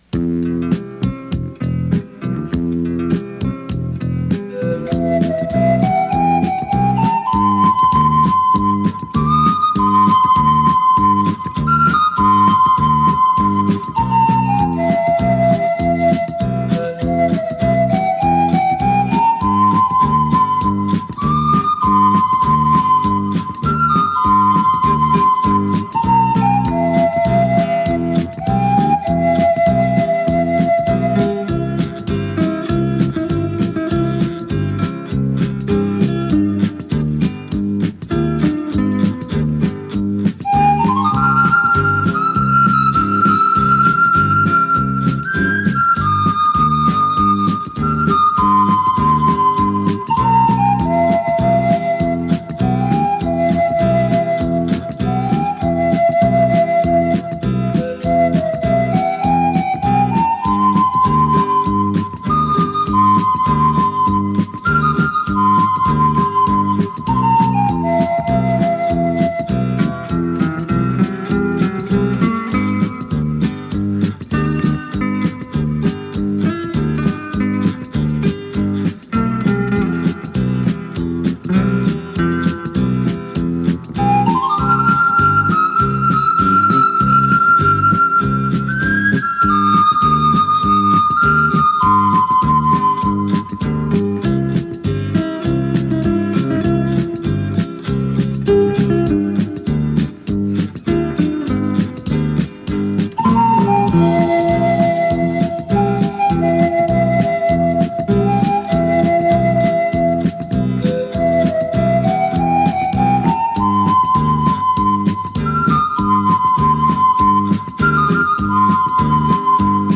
pan flute